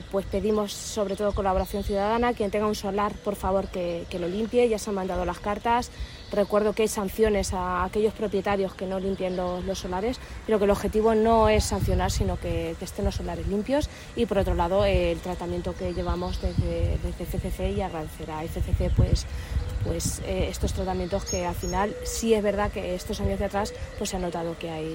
concejala_solares.mp3